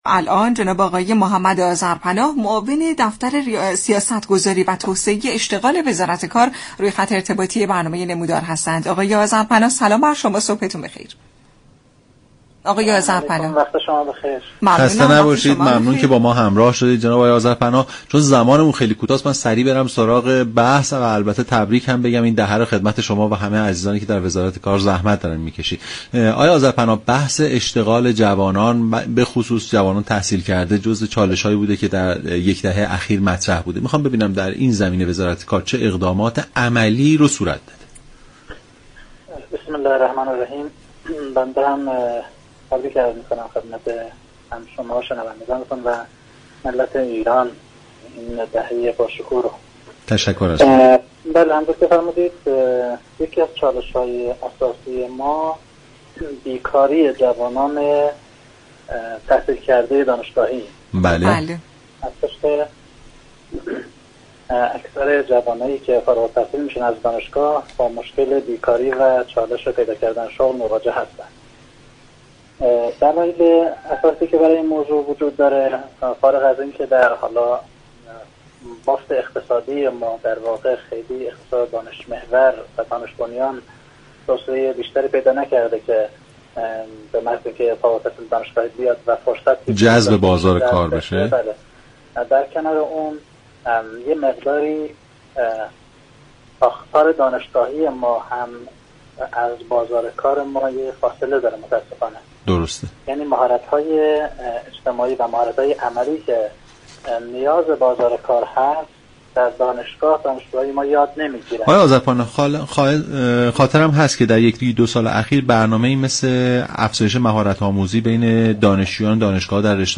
«محمدآذرپناه» معاون دفتر سیاستگذاری و توسعه اشتغال وزارت تعاون، كار و رفاه اجتماعی در برنامه «نمودار» رادیو ایران گفت : ارتباط دانشگاه و كارگاه های صنعتی ، یك تعامل برد-برد است